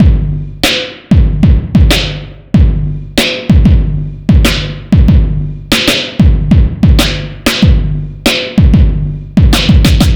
Track 13 - Drum Break 05.wav